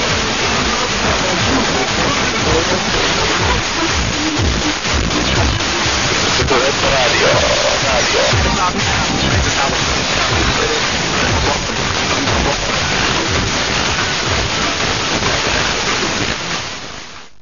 Signals were mostly strong and clear.
• 91.2 MHz - UNID, perhaps Serbian. ID in noises - 16.25-